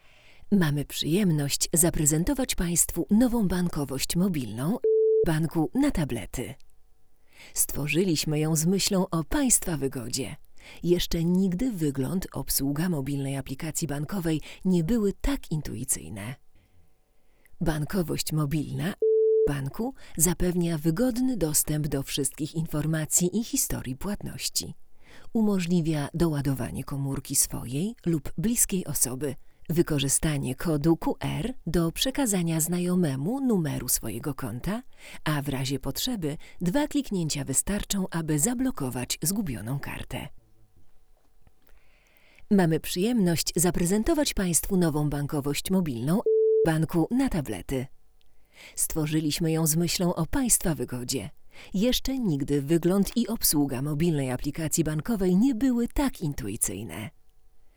Mikrofon nie posiada przełącznika charakterystyk, pracuje w kardioidzie i z lektorskiego punktu widzenia, nie jest to wadą.
Tym razem mamy jednak do czynienia z lampą, więc musimy pogodzić się z wyraźnym wzrostem niechcianego szumu. Ma on lekki przydźwięk, na pewno nie jest przyjemy, ale naszym zdaniem nadal mieści się w normie.
Dźwięk jest nasycony, bogaty, śmiało można powiedzieć pełny.